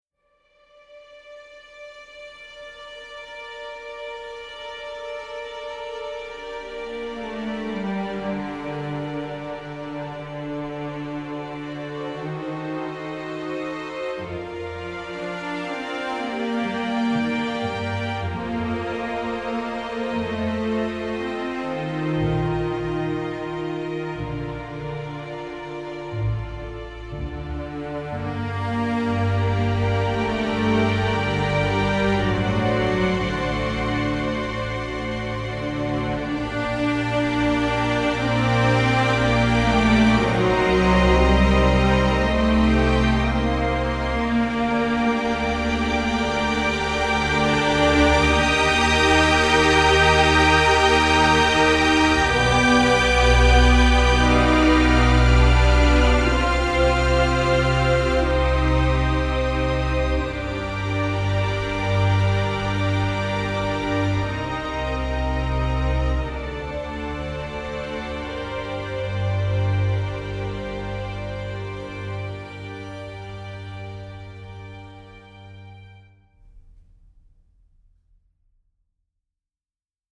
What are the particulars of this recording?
The result is a string library that is easy to use, composer-friendly, with unparalleled recording quality.